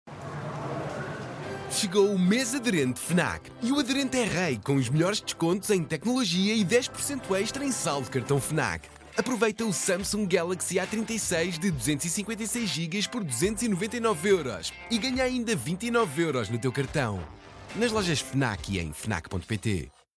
foram criadas duas abordagens de spot de rádio: uma versão institucional, focada no conceito da campanha: